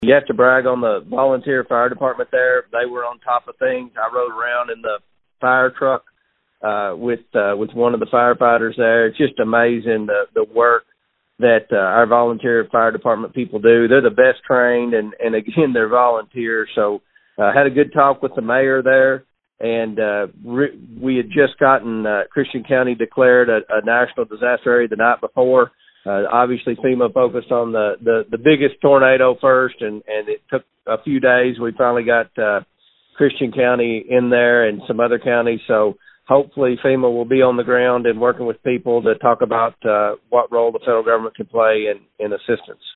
Comer told the News Edge the tornado damage in Pembroke mirrors what he saw in other parts of the first district.